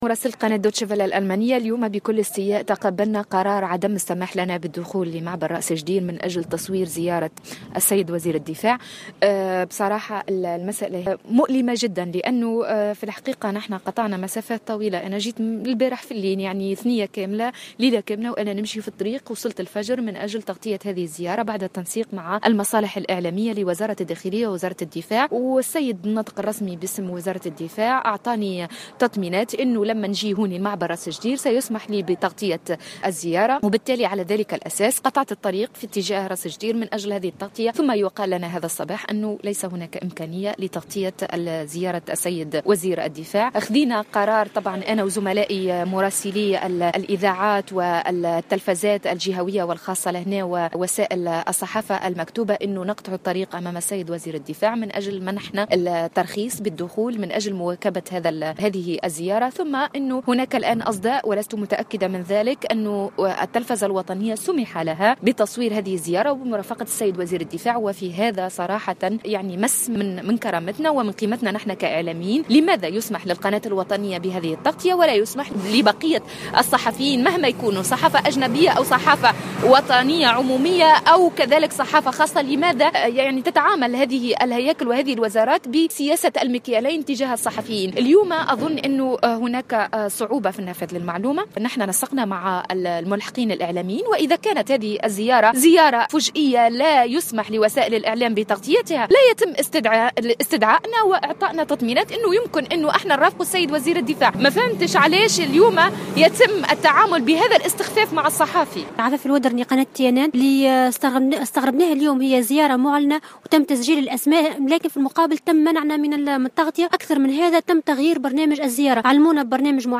تصريحات عدد من الصحفيين